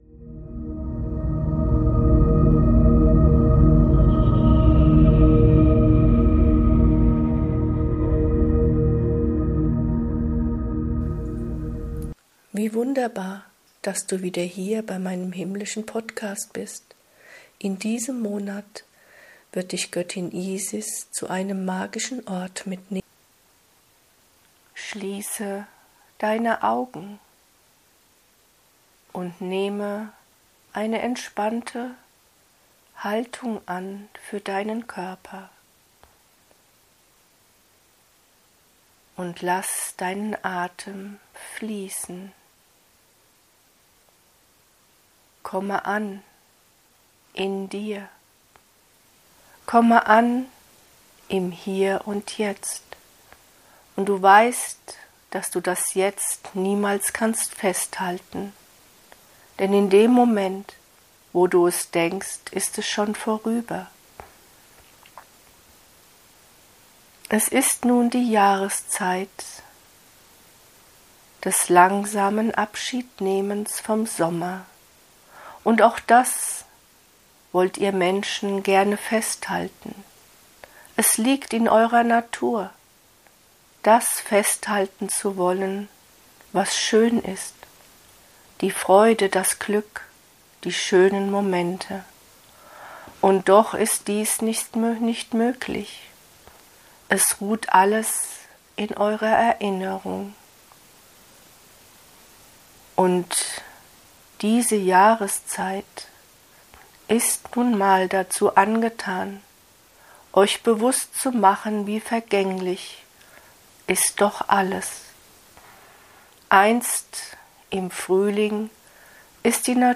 In meinem Podcast findest du durch mich direkt gechannelte Lichtbotschaften. Wundervoll geeignet zum meditieren, vom Alltag abschalten und abtauchen in eine andere Ebene des Seins.